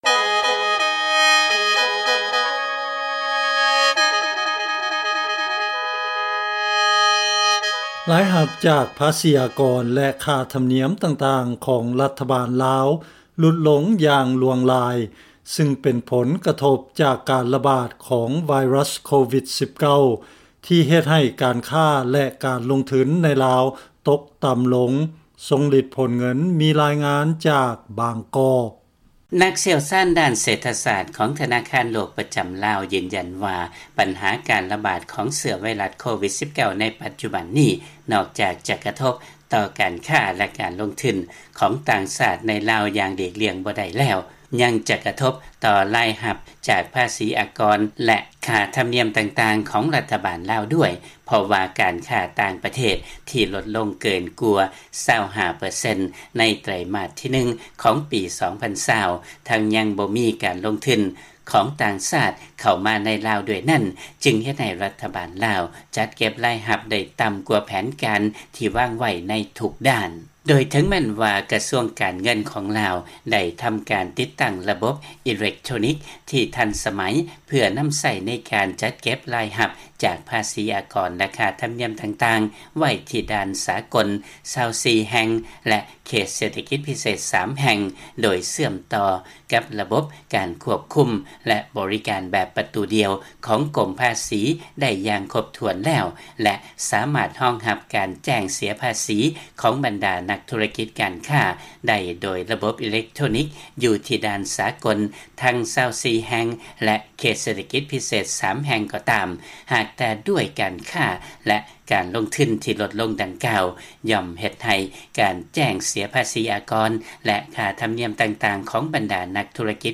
ເຊີນຟັງລາຍງານຜົນກະທົບຂອງການລະບາດຂອງພະຍາດໂຄວິດ-19 ຕໍ່ການເກັບລາຍຮັບຂອງລາວ